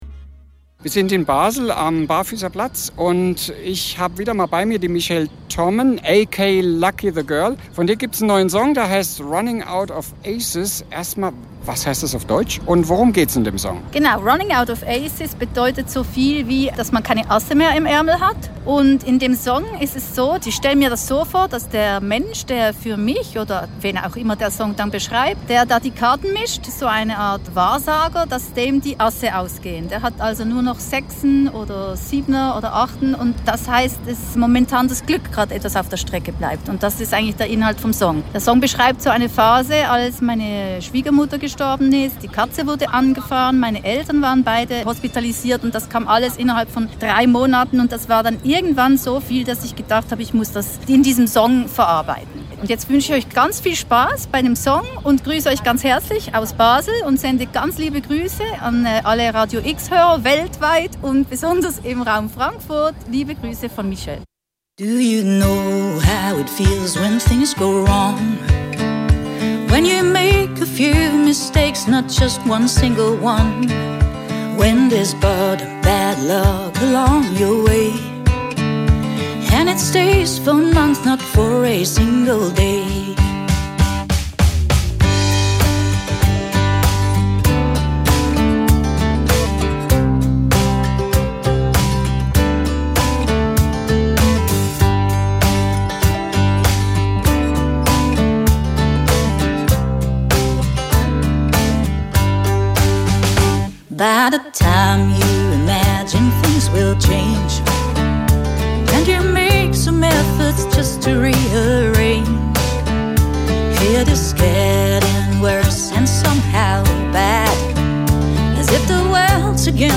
(live aus dem Berner Generationenhaus), 10.10.2022, Stream on Soundcloud